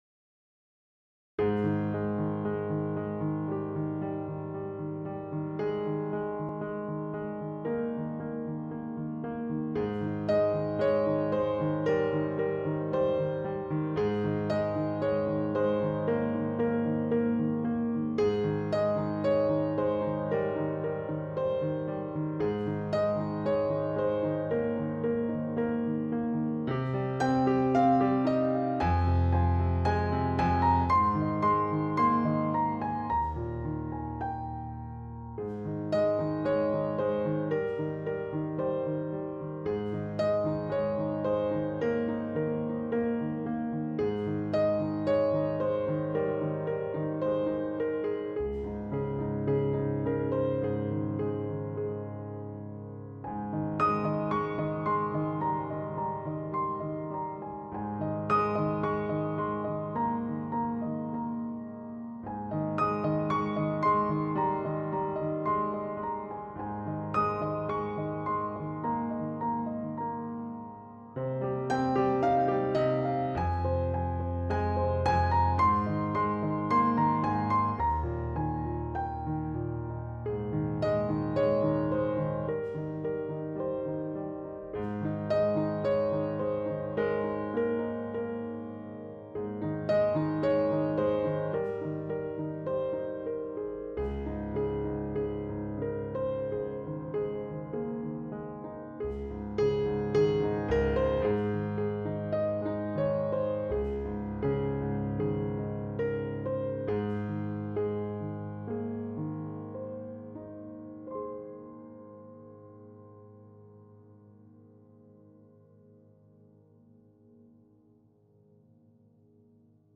LBfhNREytfh_10-Minutes-Timer-with-Relaxing-music-to-improve-your-Focus-relaxed.mp3